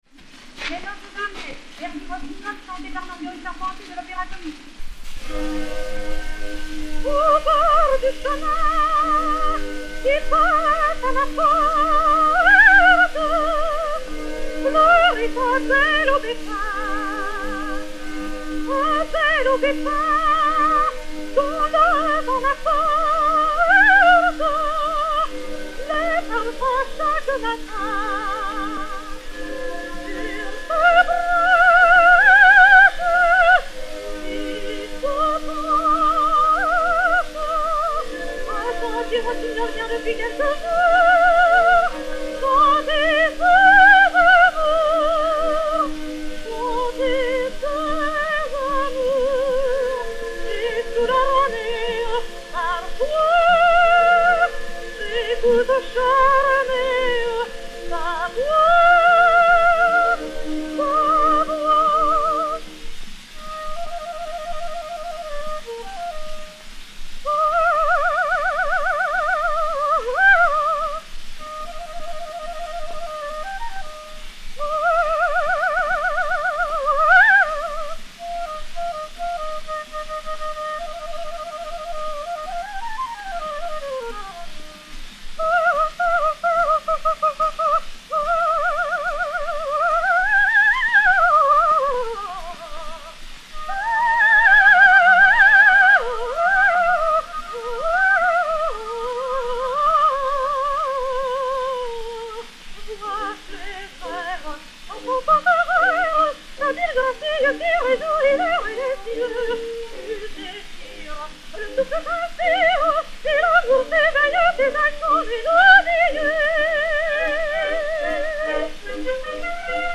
soprano de l'Opéra-Comique Orchestre
et Orchestre
Pathé saphir 90 tours n° 527, réédité sur 80 tours n° 132, enr. en 1911